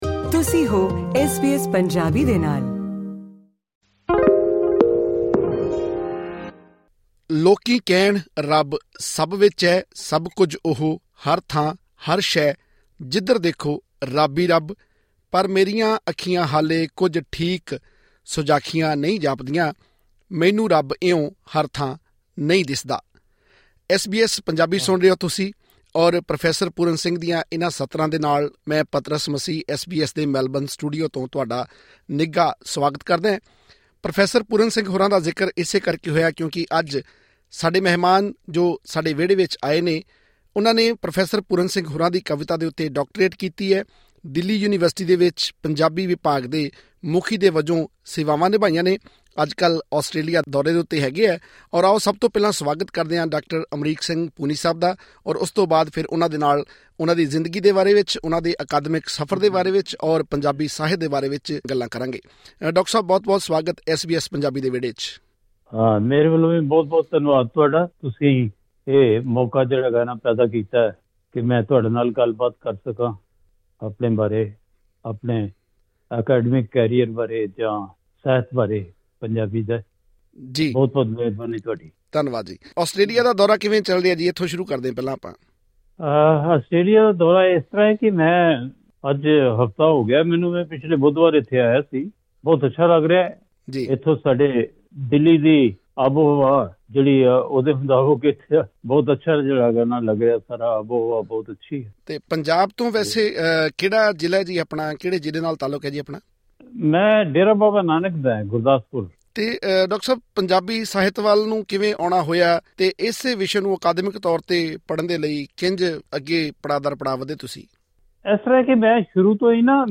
ਪੰਜਾਬੀ ਭਾਸ਼ਾ ਅਤੇ ਸਾਹਿਤ: ਮੌਜੂਦਾ ਸਥਿਤੀ ਤੋਂ ਭਵਿੱਖ ਦੀ ਦਿਸ਼ਾ ਤੱਕ ਵਿਚਾਰ ਚਰਚਾ